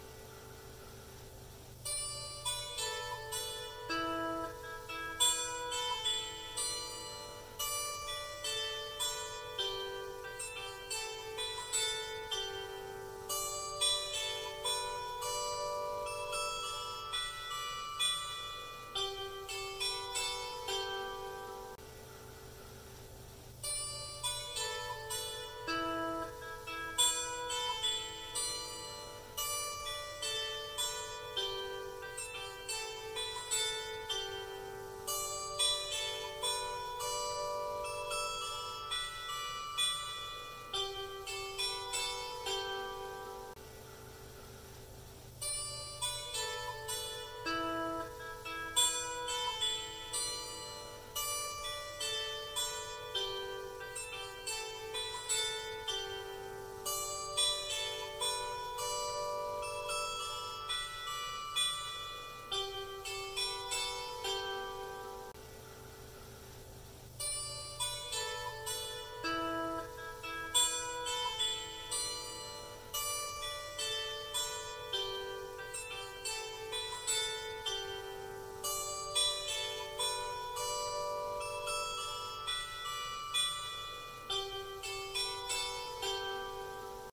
Σαπφο 31 . Оригінальний Сапфічний спів